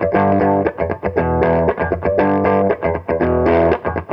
RIFF6.wav